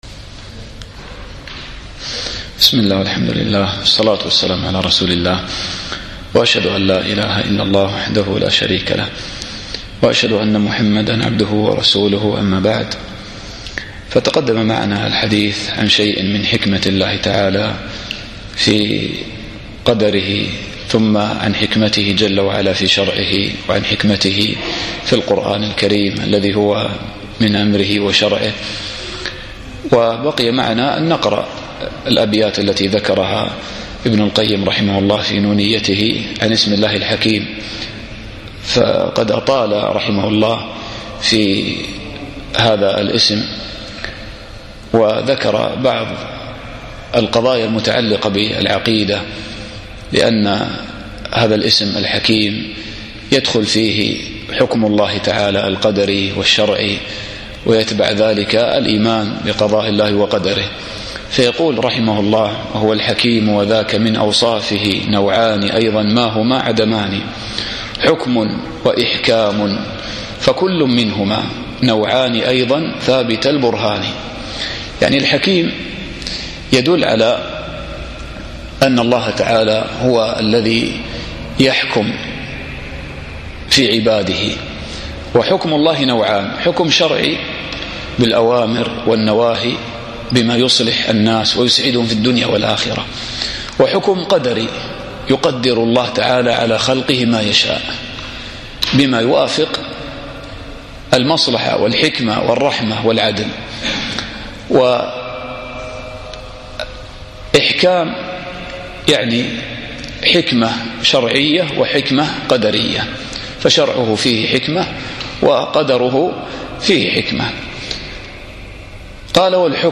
الدرس العشرون